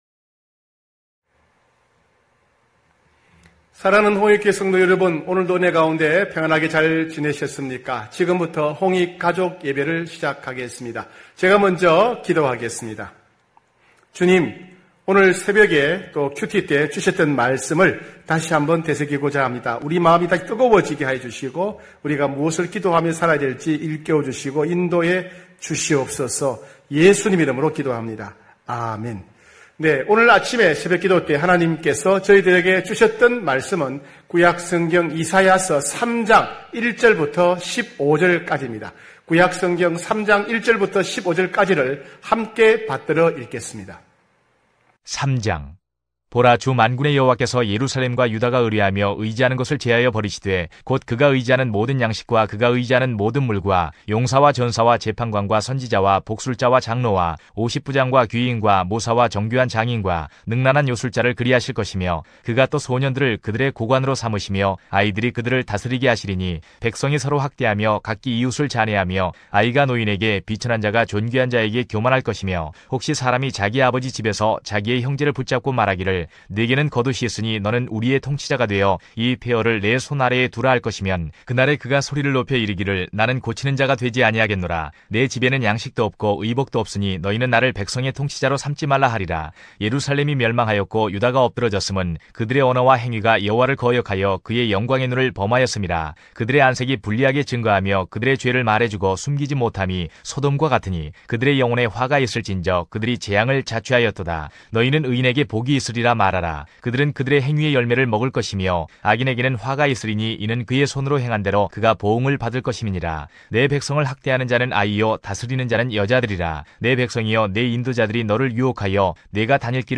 9시홍익가족예배(7월13일).mp3